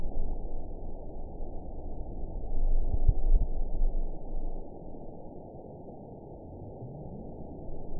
event 910692 date 01/29/22 time 20:26:49 GMT (3 years, 3 months ago) score 9.15 location TSS-AB01 detected by nrw target species NRW annotations +NRW Spectrogram: Frequency (kHz) vs. Time (s) audio not available .wav